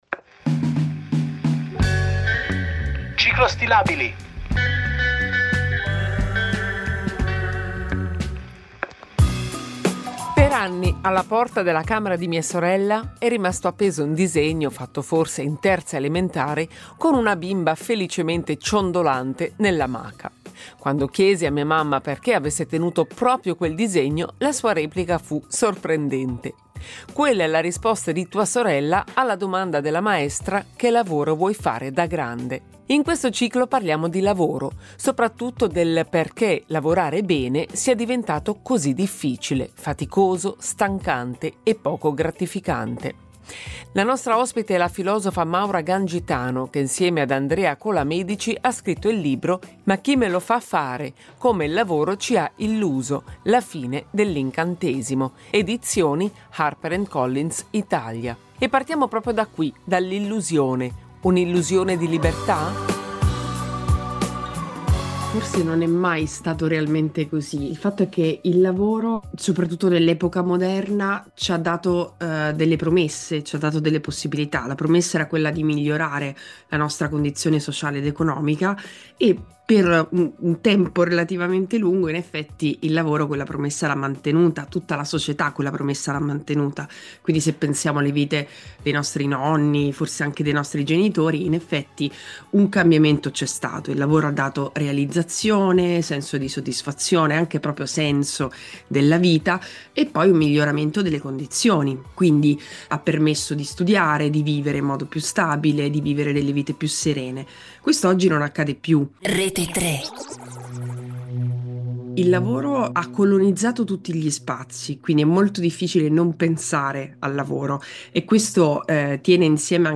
Un dialogo